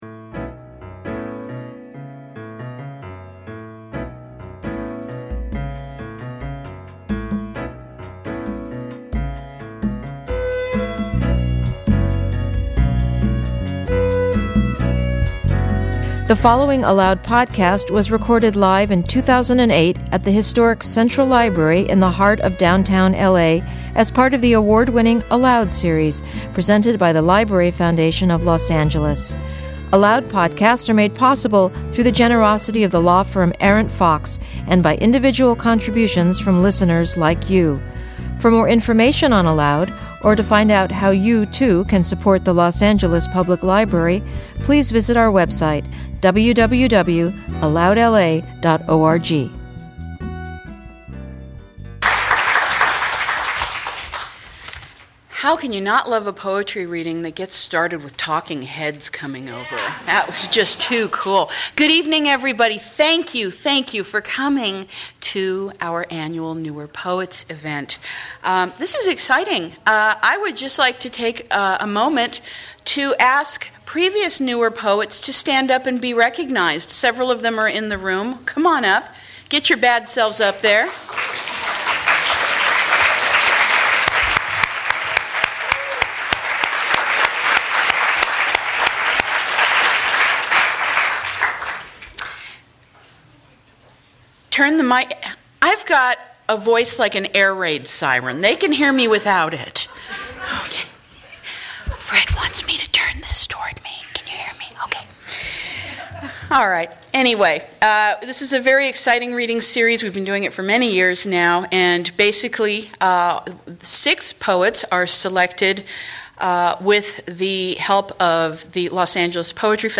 This annual poetry reading for local voices introduces a cross-section of lively, talented writers who are making an impression in the Los Angeles poetry community.